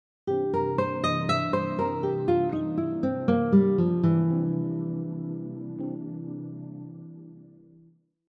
This last example blends the Dominant arpeggio with a C blues scale in measure 1.
Dominant 7 arpeggio example 5
Dominant-7-arpeggio-example-5.mp3